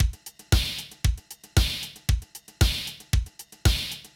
MOO Beat - Mix 3.wav